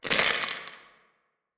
328d67128d Divergent / mods / Soundscape Overhaul / gamedata / sounds / ambient / soundscape / underground / under_31.ogg 46 KiB (Stored with Git LFS) Raw History